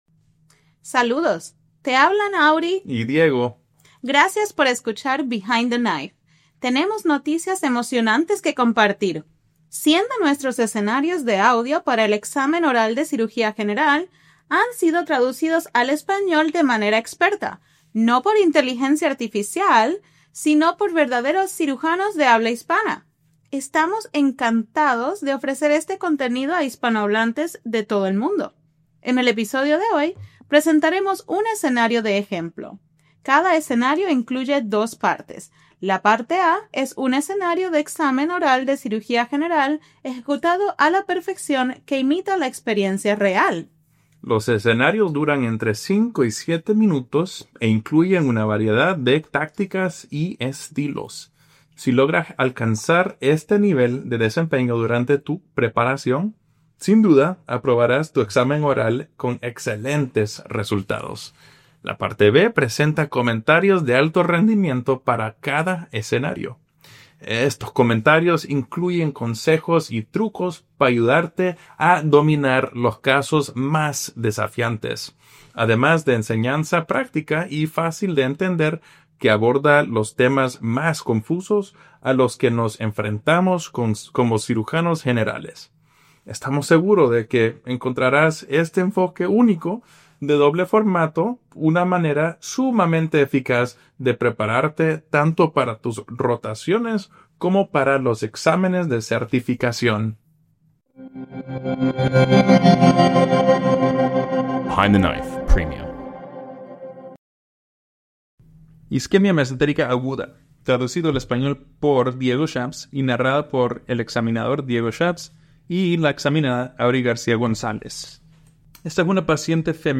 La primera parte es un caso oral perfectamente ejecutado que imita la realidad.